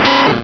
-Replaced the Gen. 1 to 3 cries with BW2 rips.
raticate.aif